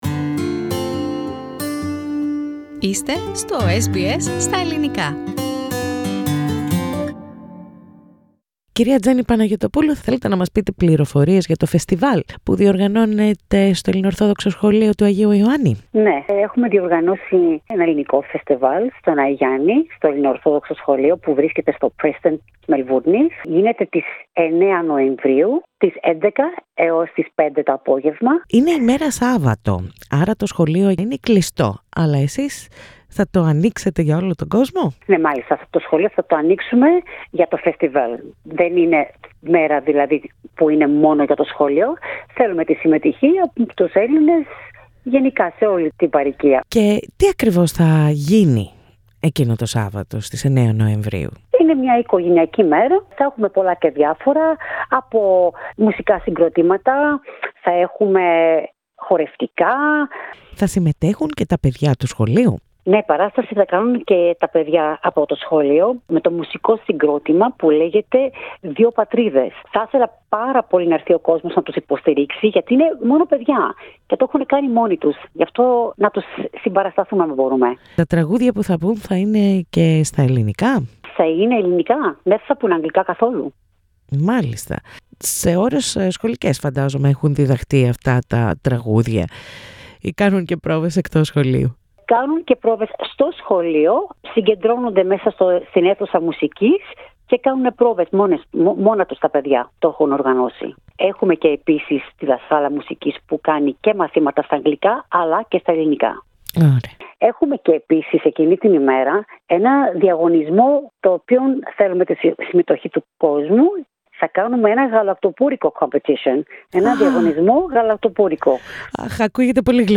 Ακούστε την συνέντευξη πατώντας play στο podcast που συνοδεύει την αρχική φωτογραφία.